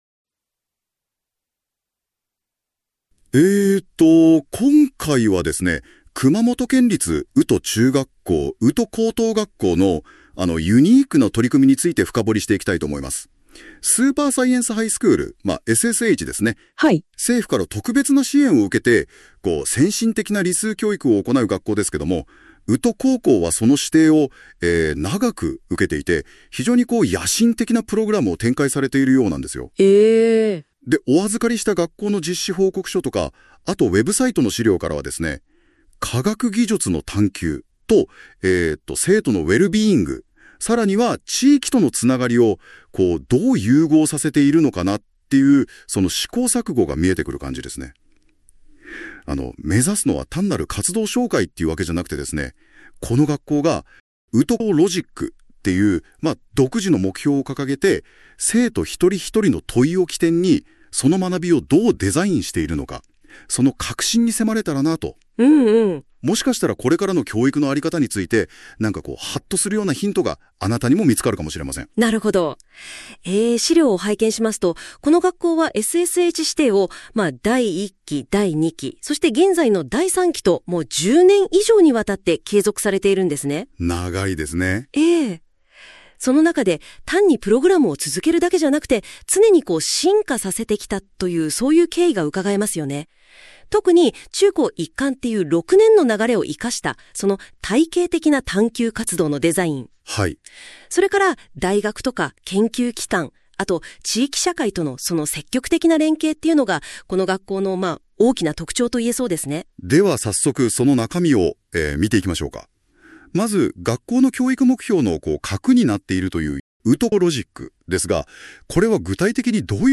音声はAIを利用しているため、乱れる場合があります。
第三期_前半radio式音声紹介.mp3